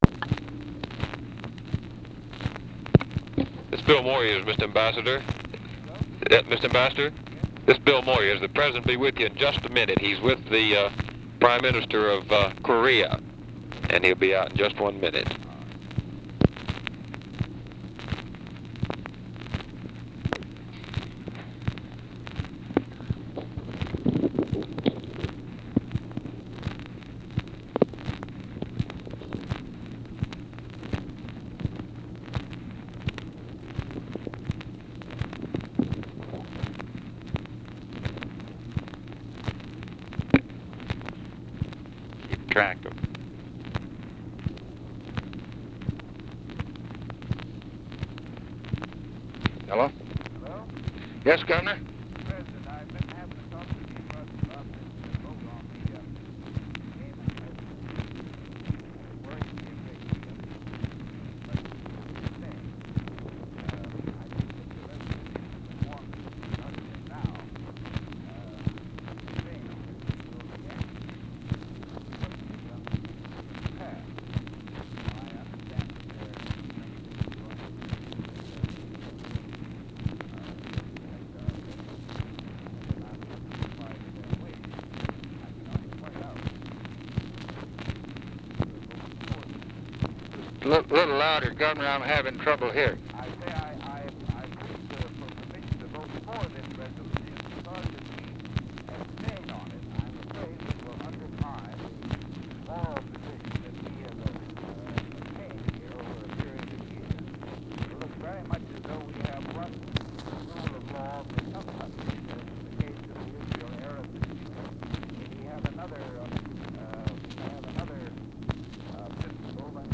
Conversation with ADLAI STEVENSON and BILL MOYERS, April 9, 1964
Secret White House Tapes